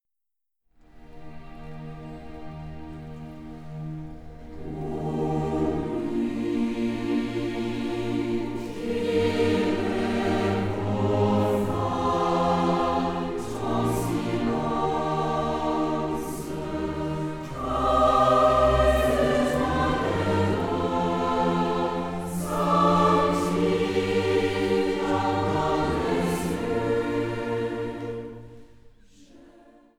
Chor, Klavier